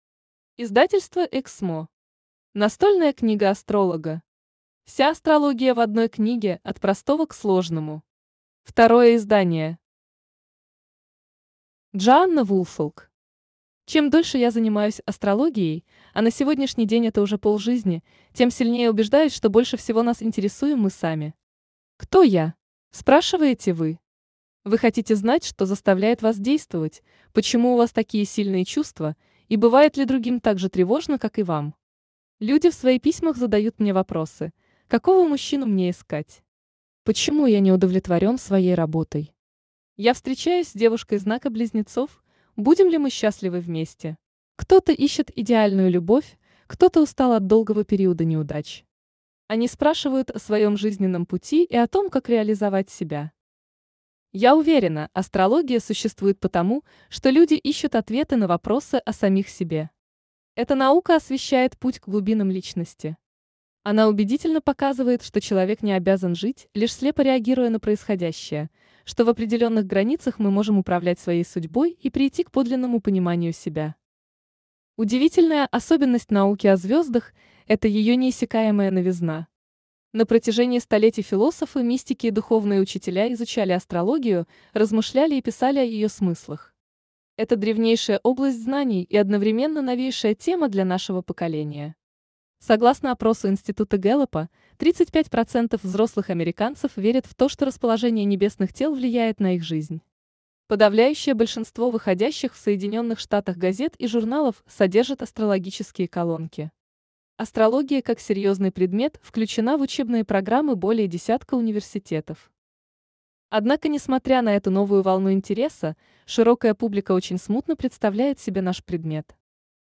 Аудиокнига Настольная книга астролога. Вся астрология в одной книге – от простого к сложному | Библиотека аудиокниг
Вся астрология в одной книге – от простого к сложному Автор Джоанна Мартин Вулфолк Читает аудиокнигу Искусственный интеллект Элина.